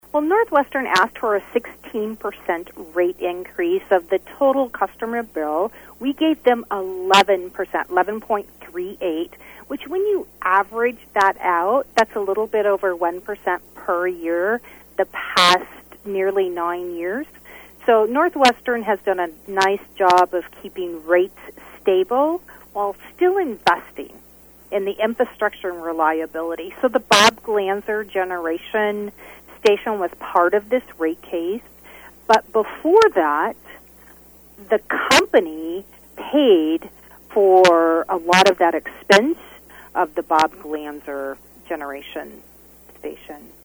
The increase was the first in nearly 9 years for Northwestern.  Fiegen said in that time Northwestern still invested in improvements to the service chain.